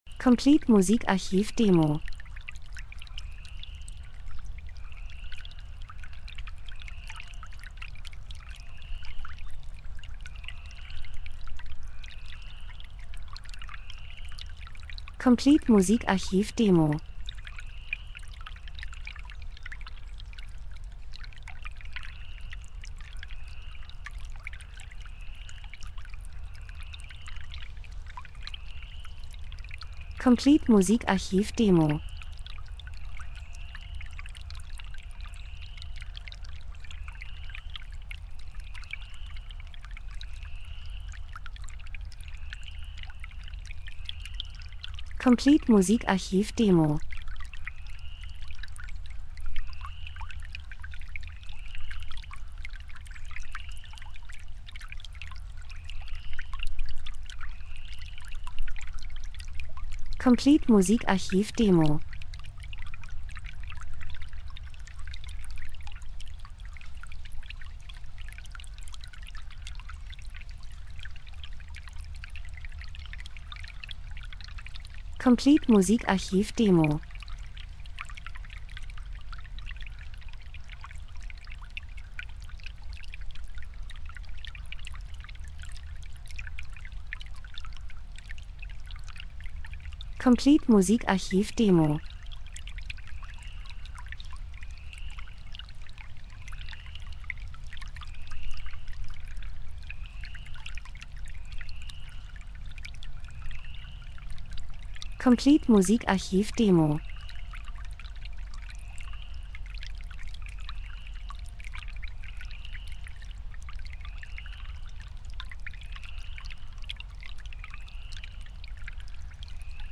Frühling -Geräusche Soundeffekt Natur Vögel Wind Wasser 02:08